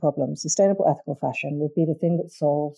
Removing whistling/screeching sound
I am a bit of an Audacity newbie and trying to edit some not great audio. I have (helped a lot by reading around on this forum) managed to solve various problems, but can’t seem to solve a whistling/screeching noise around certain letters and sounds (usually involving a “sh” combination) in my recording.